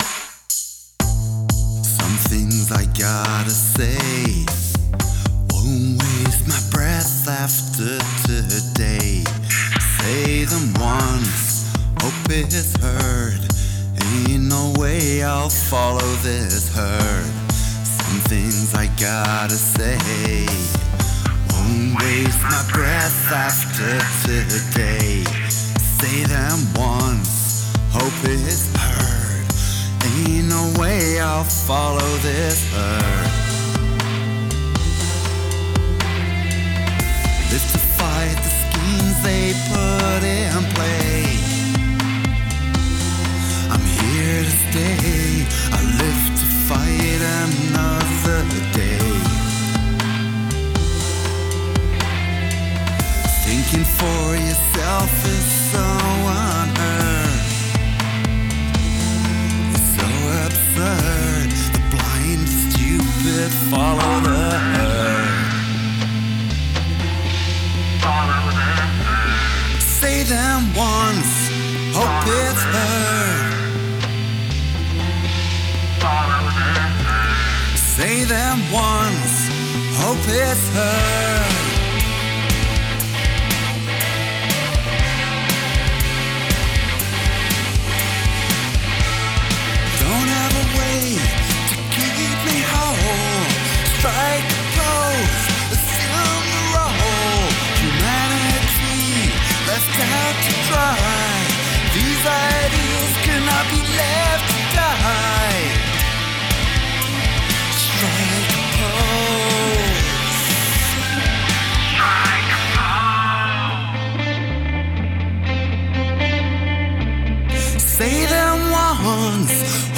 industrial punk rock sort of